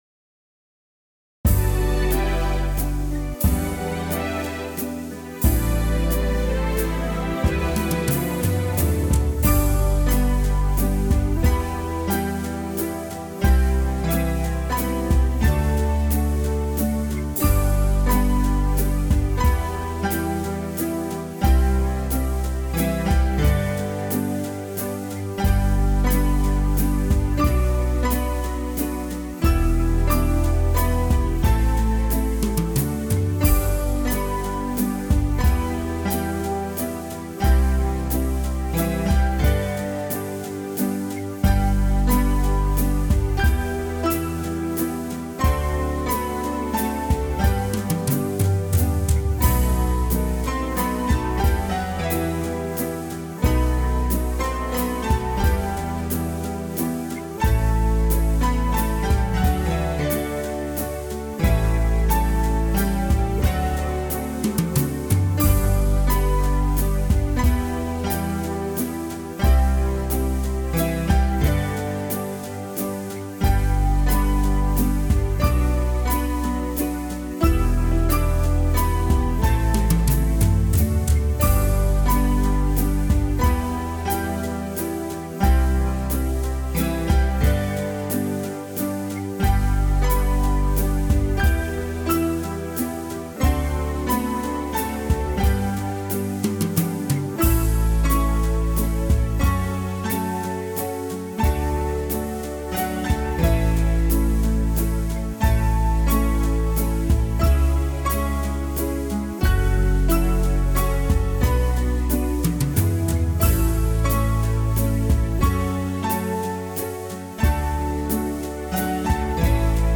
Walzer